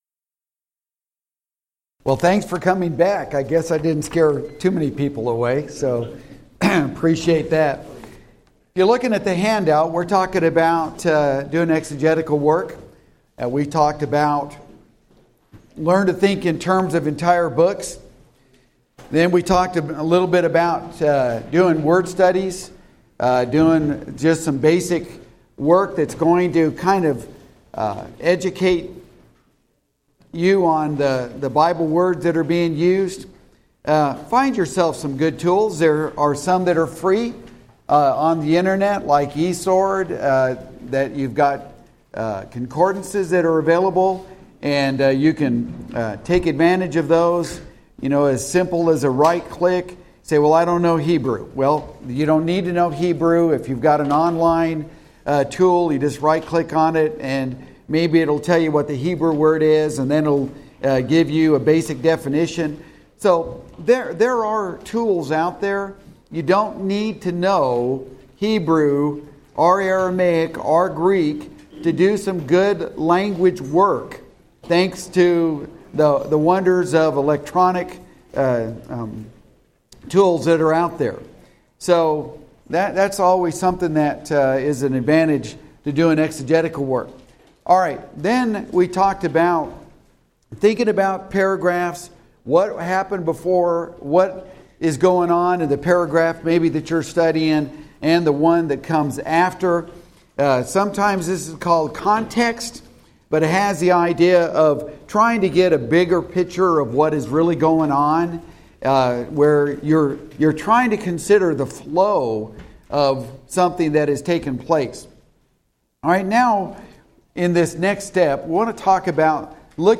Event: 2014 Focal Point
Preacher's Workshop